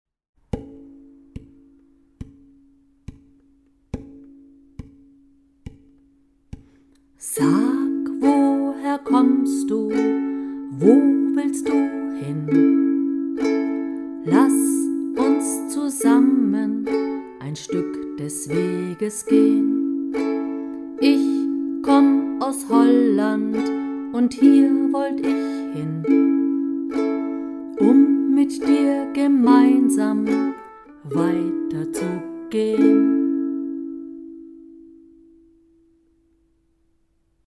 Langsame Fassung zum Üben: